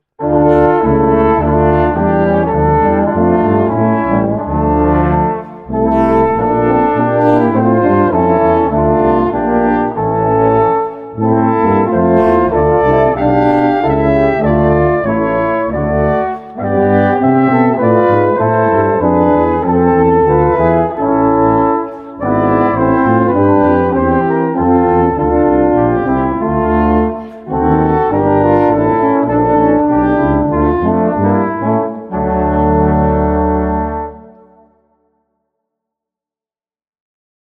Barocke Kirchenmusik für Blechblasinstrumente
2x Flügelhorn, F-B-Horn, Es-Tuba, B-Tuba [0:37]